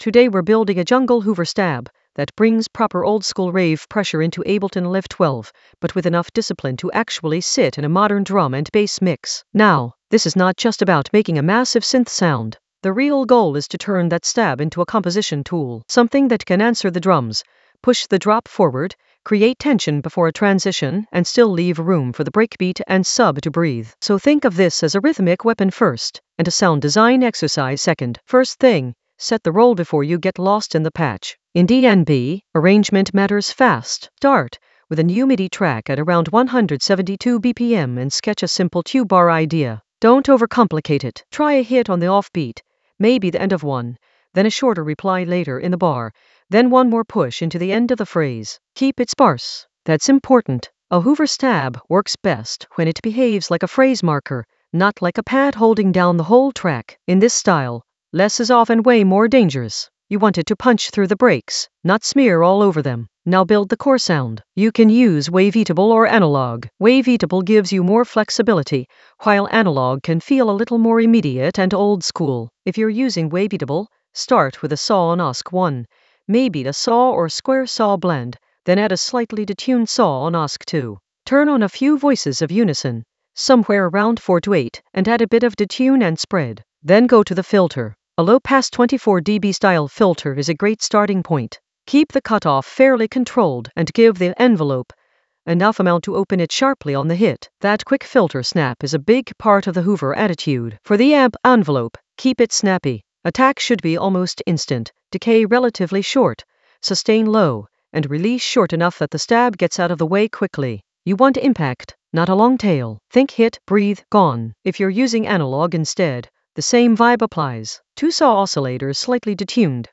An AI-generated intermediate Ableton lesson focused on Route jungle hoover stab for oldskool rave pressure in Ableton Live 12 in the Composition area of drum and bass production.
Narrated lesson audio
The voice track includes the tutorial plus extra teacher commentary.